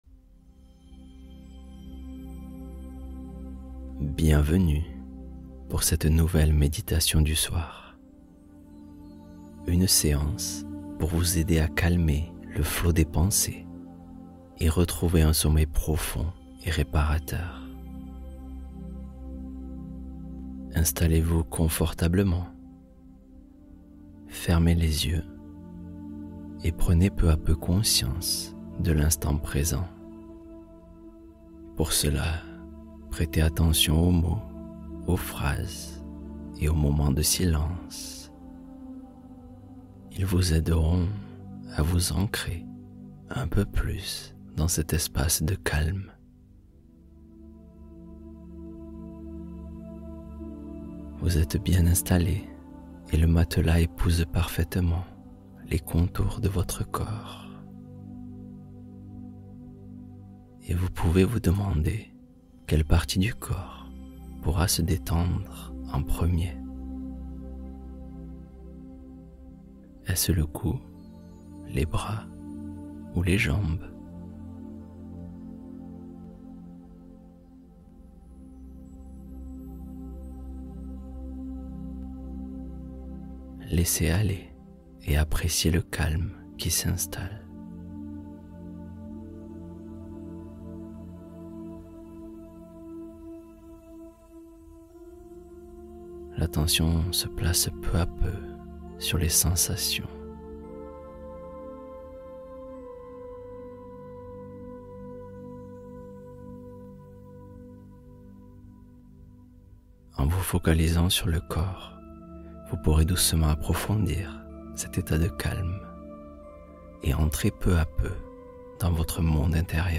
Sommeil profond : histoire guidée pour s’endormir sans effort